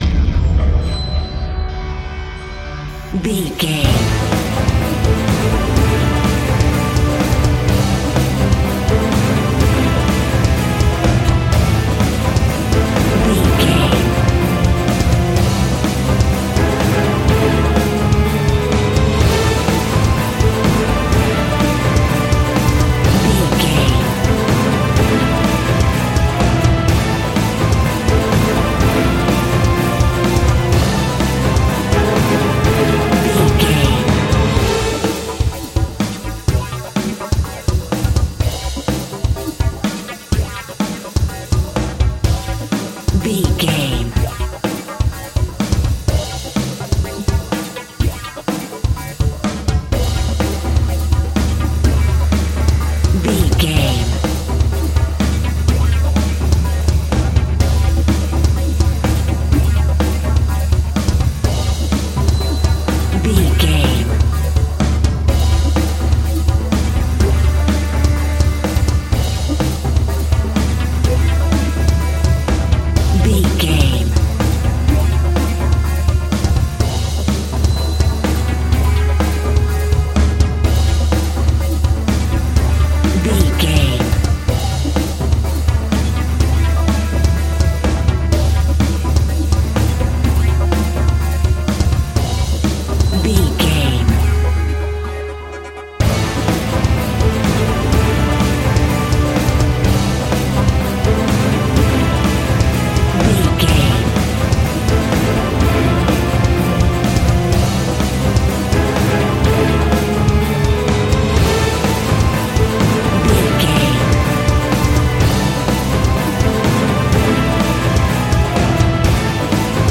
In-crescendo
Thriller
Aeolian/Minor
Fast
tension
ominous
dark
dramatic
eerie
drums
strings
synths
electronics
staccato strings
staccato brass
viola
french horn
taiko drums
glitched percussion